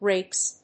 発音
• / reps(米国英語)
• / reɪps(英国英語)